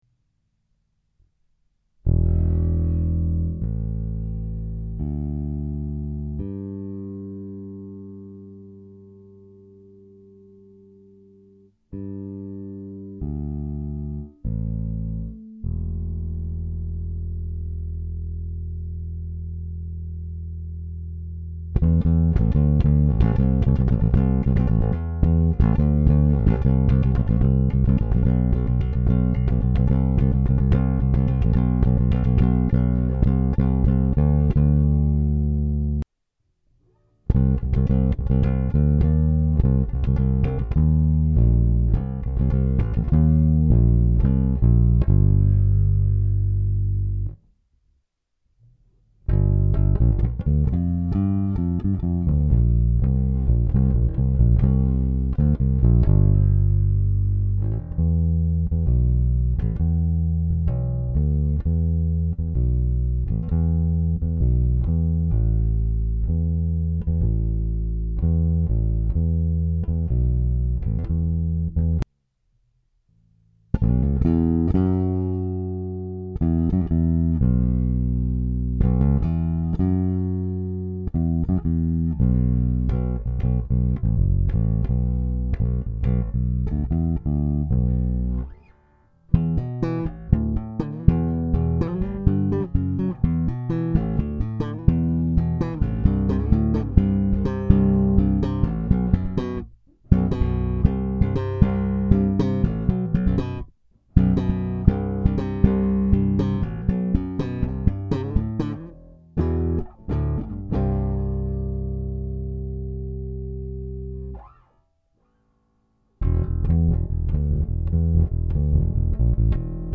Upgrade your bass with a large , rich , powerful sound.
Neo provides warmth and fullness along with tons of low end.
Neo P Bass sound Clip